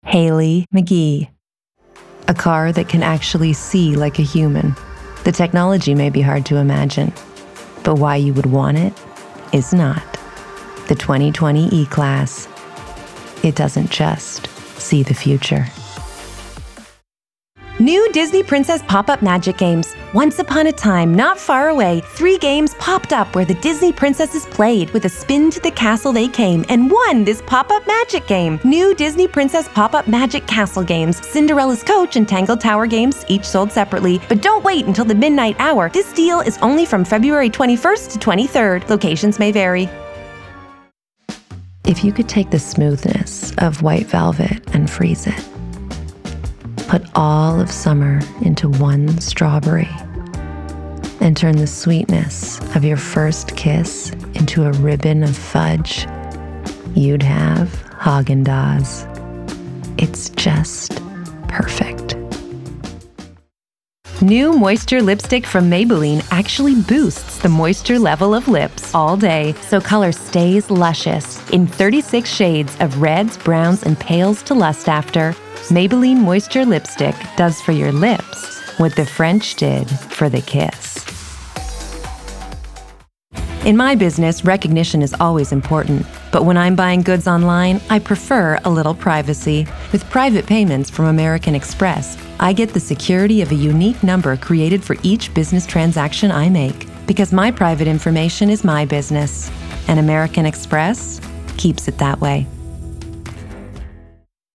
Commercial Reel